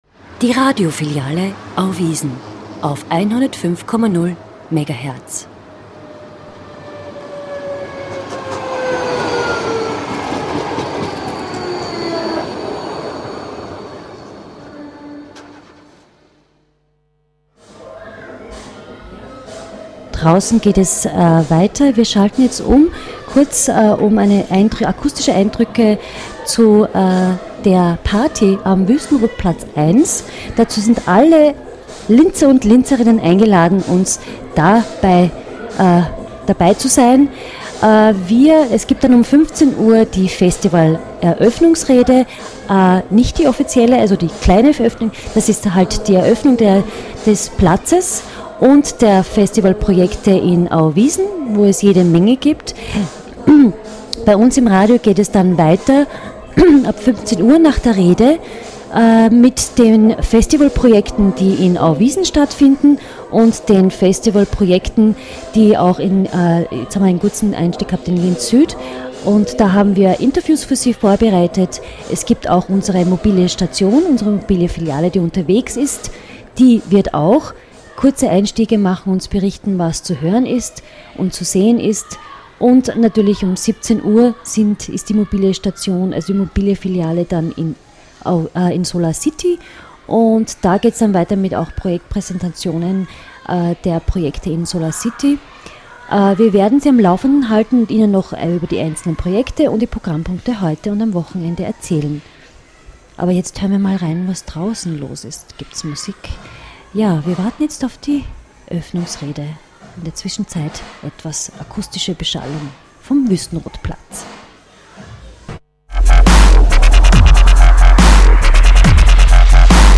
Ein Auschnitt vom Radio Brunch am Eröffnungstag: | Filiale Auwiesen
Live aus Auwiesen am Eröffnungstag